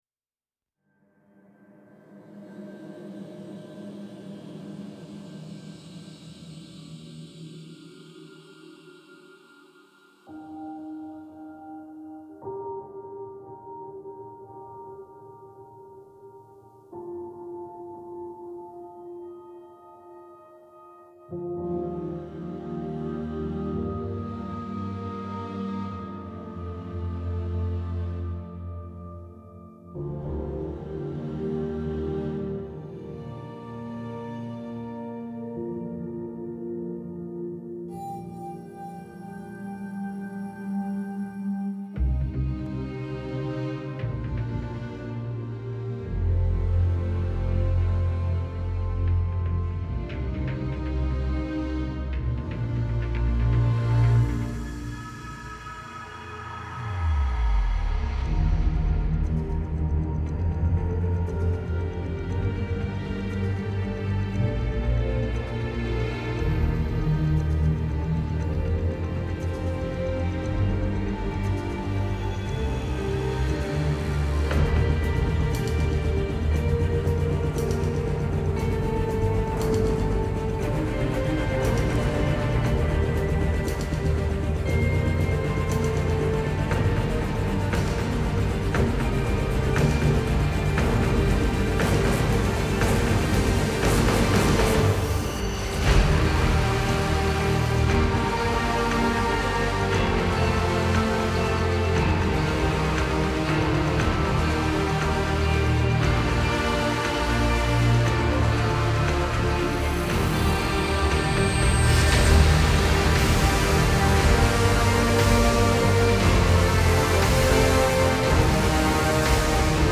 موسیقی متن موسیقی بیکلام
موسیقی حماسی